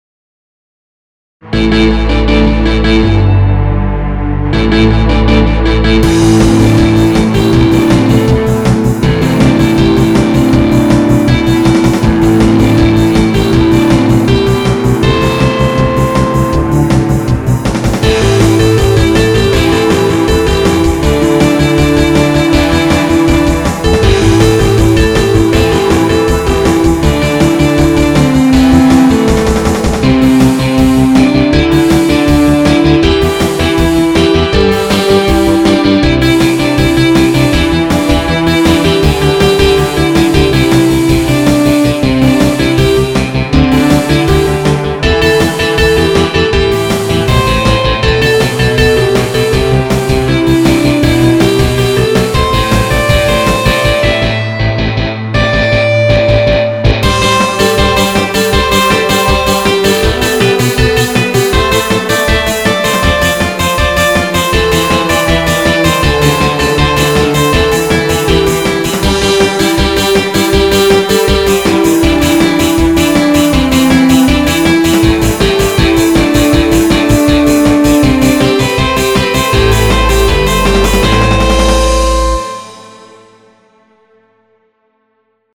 BGM
暗い